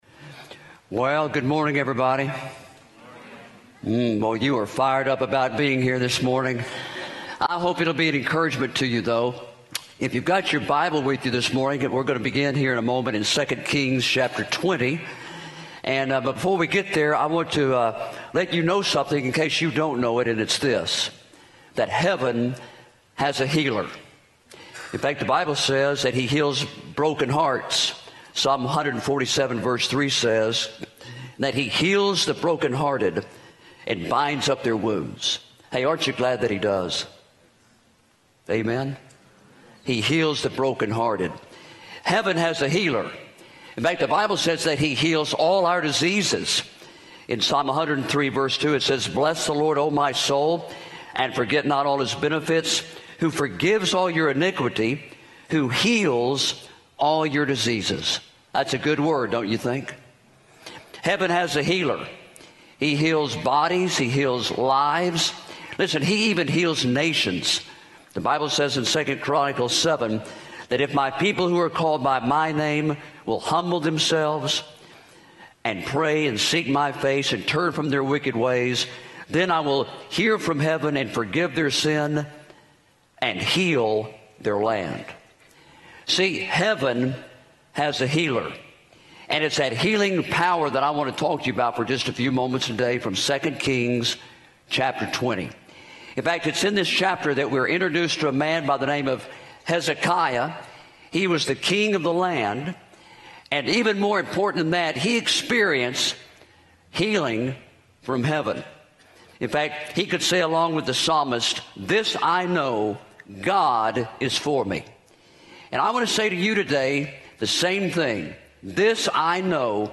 Address: "Heaven's Healer" from 2 Kings 20:1-6